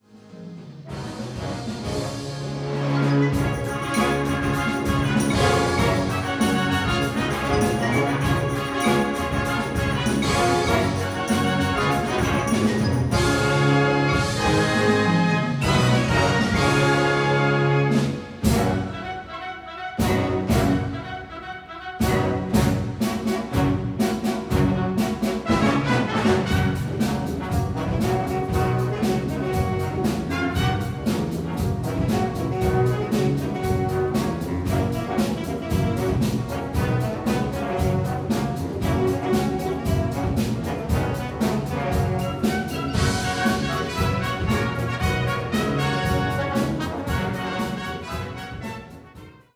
Herbstkonzert 2024